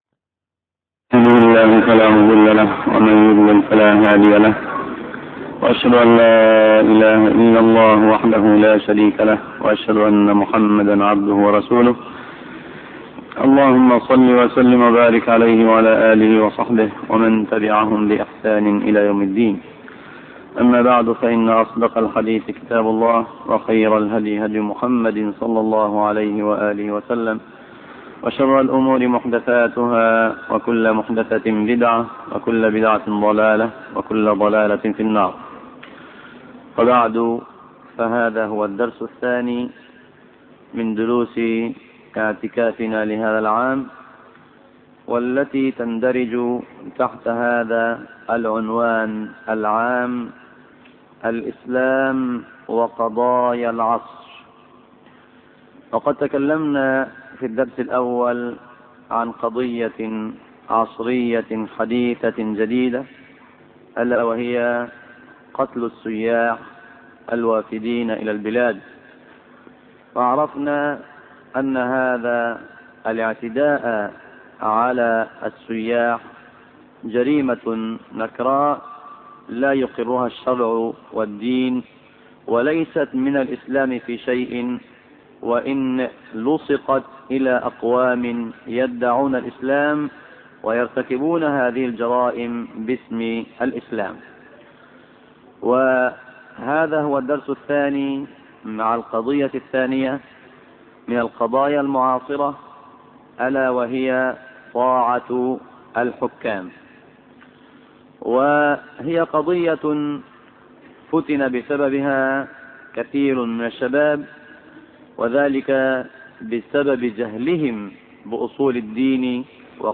عنوان المادة الدرس الثانى : الإسلام وطاعة الحكام تاريخ التحميل الأثنين 1 ديسمبر 2008 مـ حجم المادة 19.74 ميجا بايت عدد الزيارات 1,373 زيارة عدد مرات الحفظ 428 مرة إستماع المادة حفظ المادة اضف تعليقك أرسل لصديق